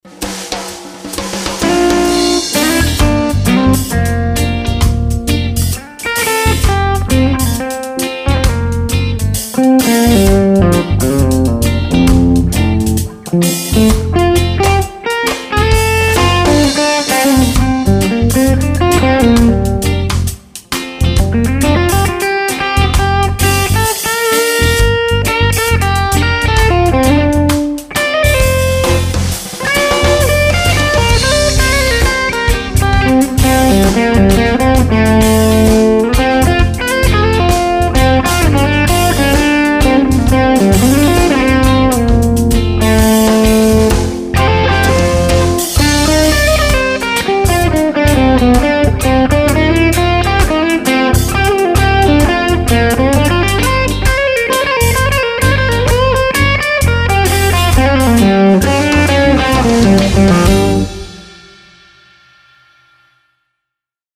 These clips are the EMU built in mic pre so no issues.
Clip from last week less gain
Duh!! I just realized I used the Audix I5 on the 185 clips....